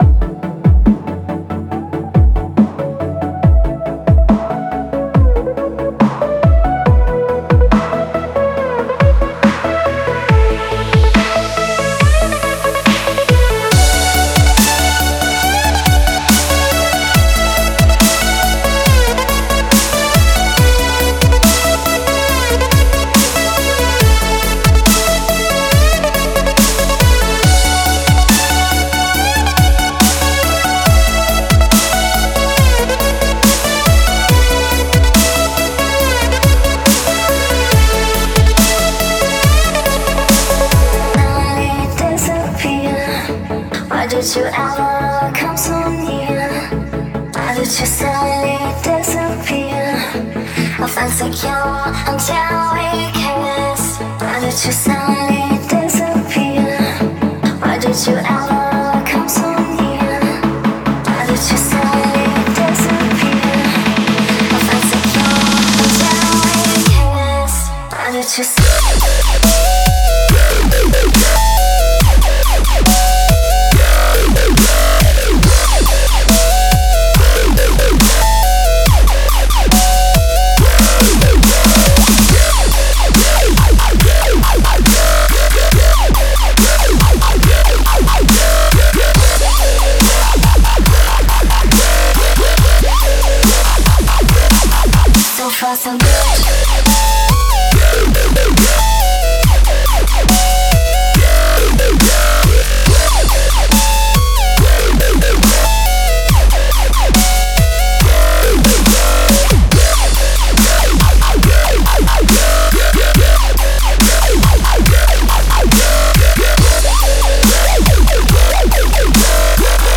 это энергичная песня в жанре поп-рок